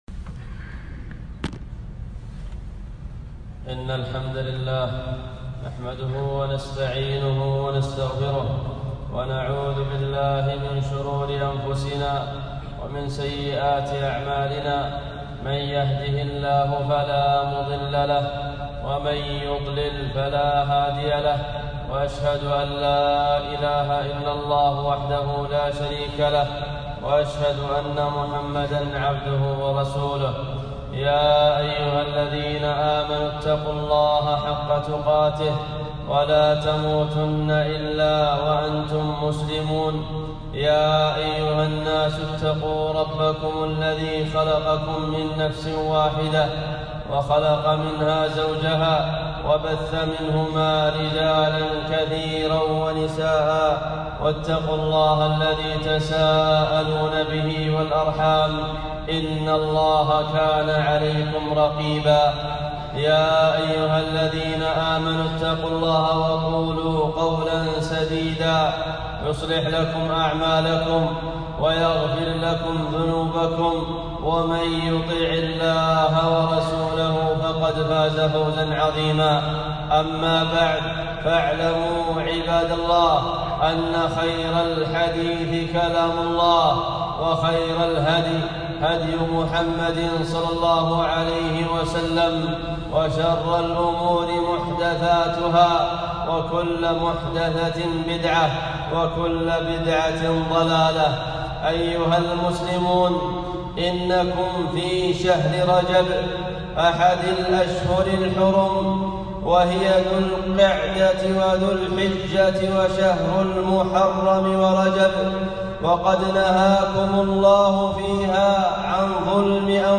خطبة - خطر الذنوب وآثارها